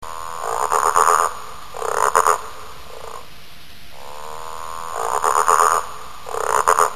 frog3.mp3